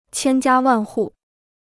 千家万户 (qiān jiā wàn hù): every family (idiom).